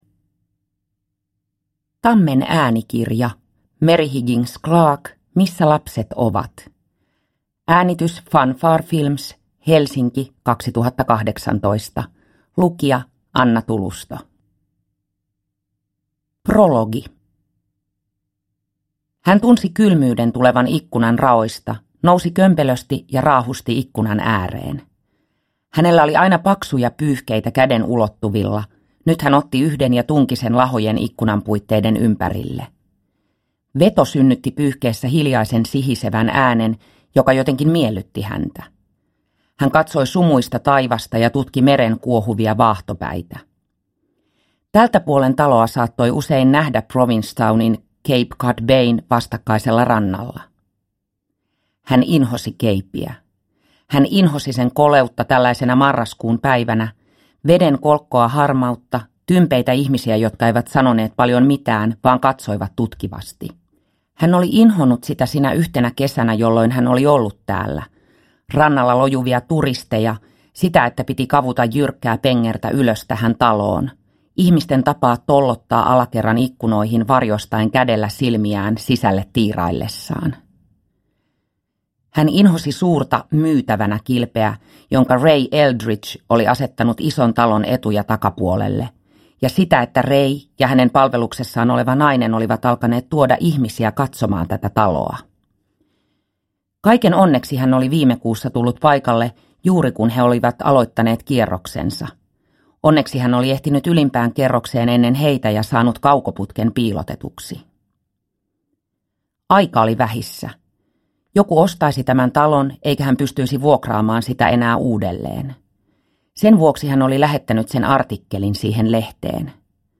Missä lapset ovat? – Ljudbok – Laddas ner